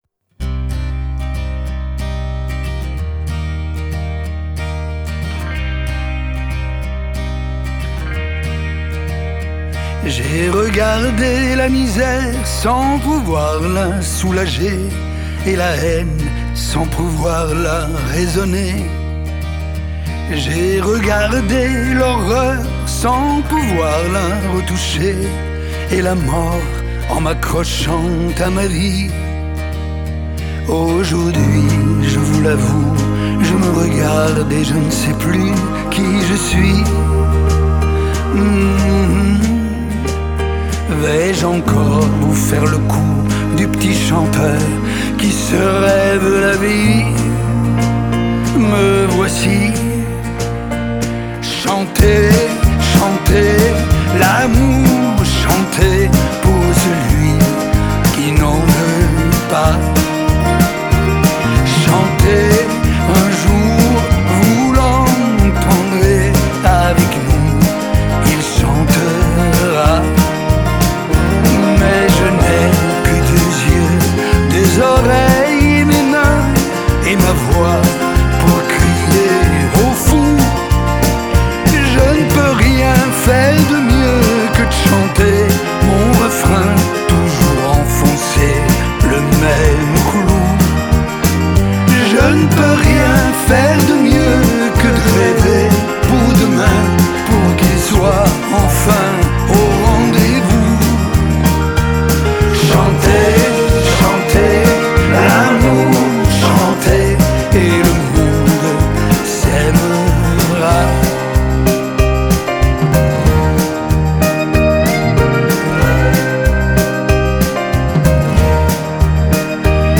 Genre: French Pop, French Chanson